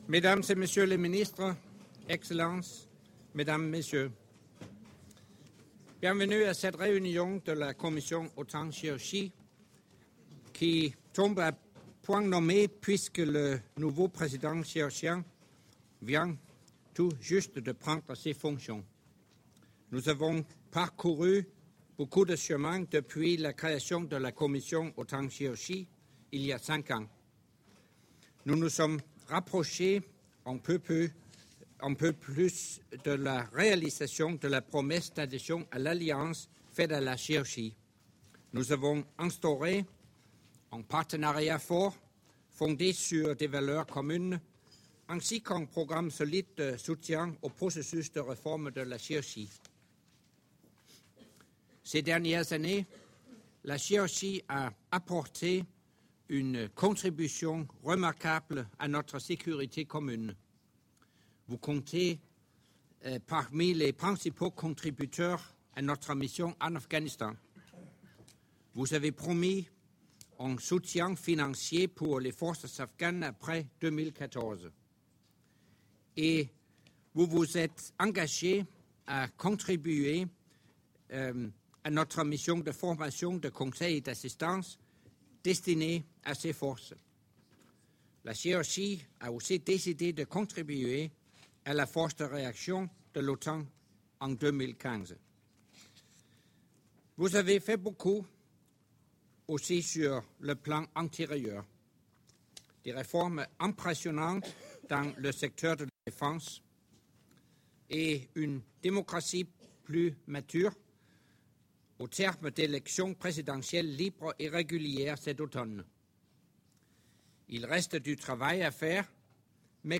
Opening remarks by NATO Secretary General Anders Fogh Rasmussen at the meeting of the NATO-Georgia Commission at the level of Foreign Affairs Ministers